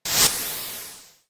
respawn.ogg